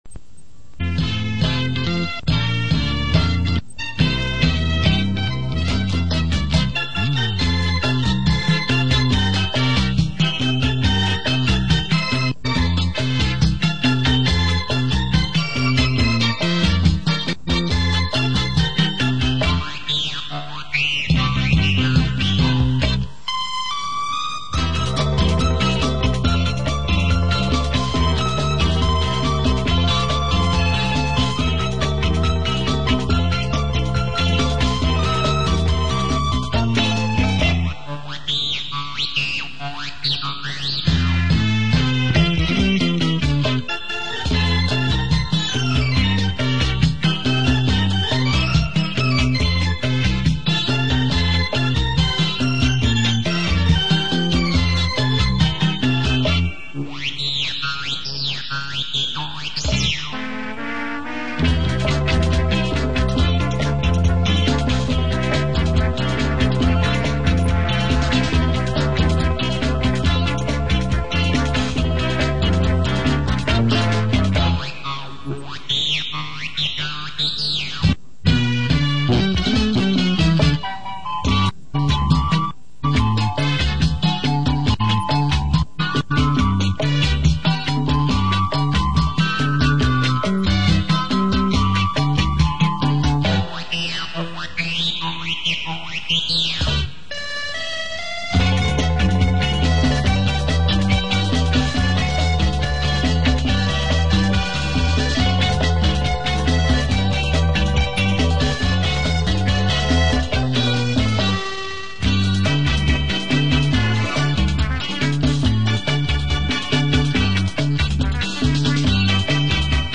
音质较差，将就着听吧，拜托了!